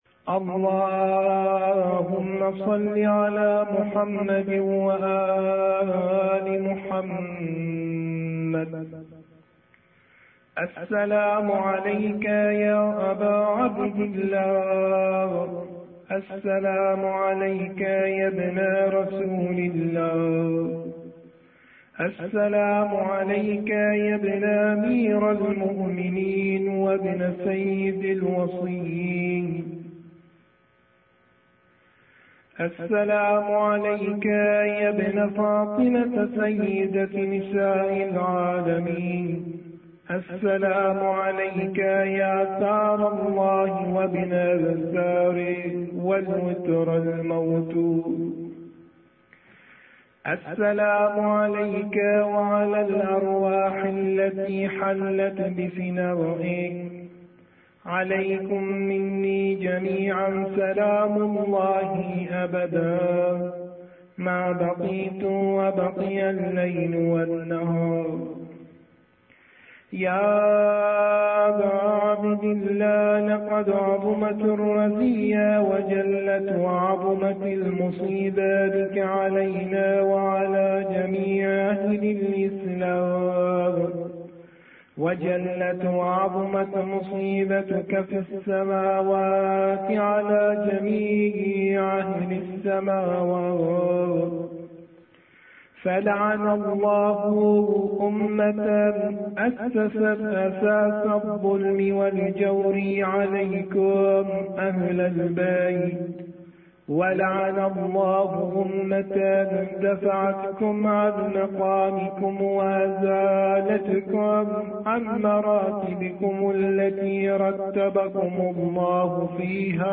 La Ziarah de 'Achoura' Avec les voix des Panégyristes Arabes et Persans